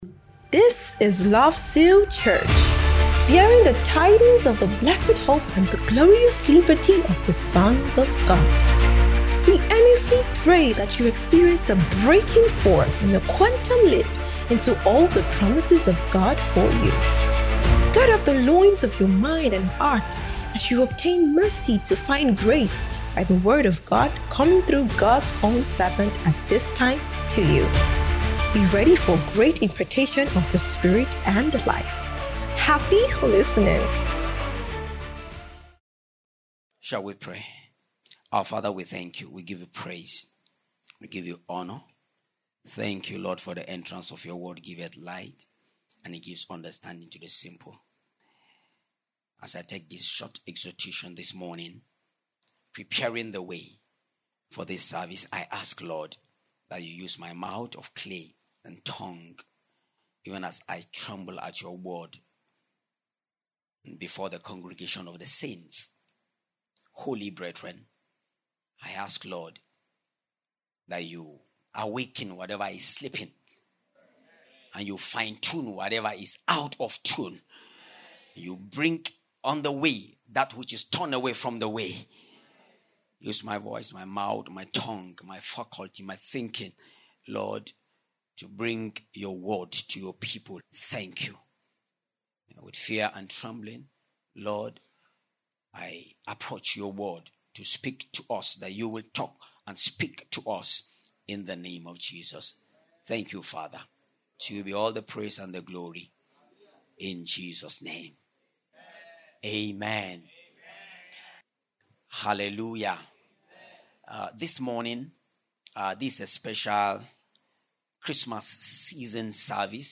Special Christmas Season Service